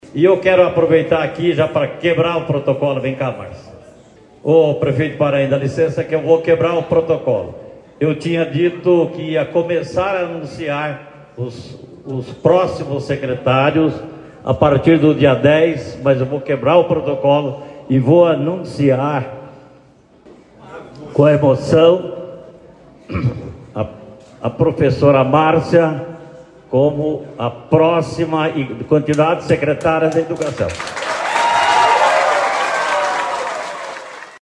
Durante evento na manhã desta quinta-feira (28) no Jardim União, Zona Sul de Cascavel, de entrega da reforma e ampliação da Escola Municipal Profª Maria dos Prazeres Neres, o prefeito eleito Renato Silva (PL) anunciou que Márcia Baldini seguirá no comando da pasta da Educação.